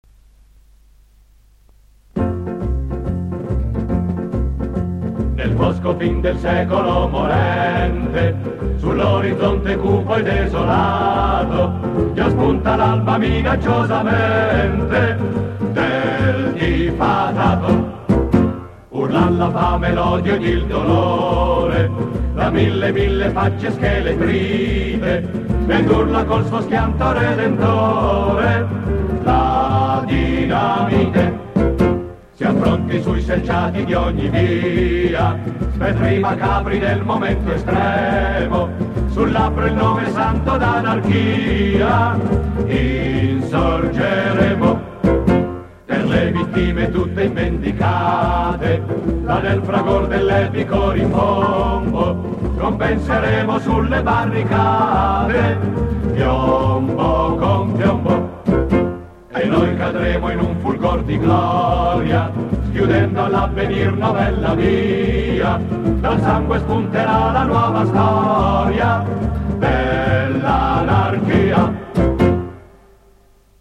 canzone anarchica